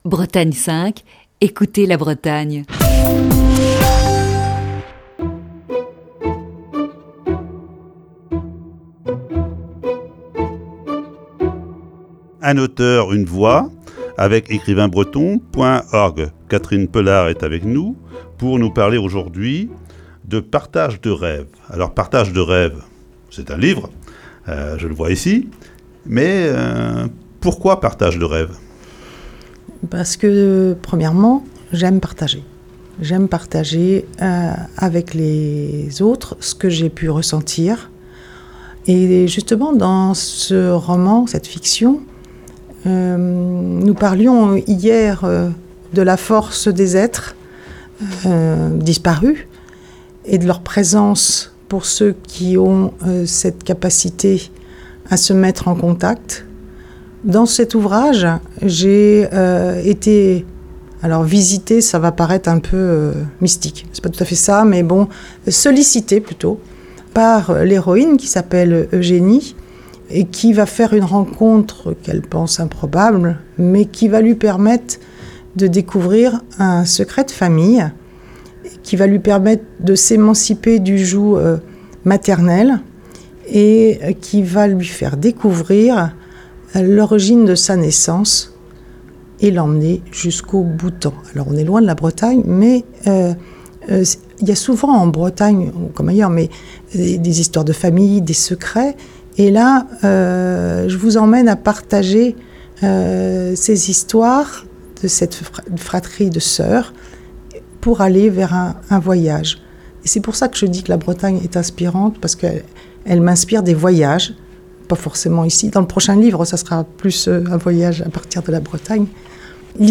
Chronique du 23 avril 2020.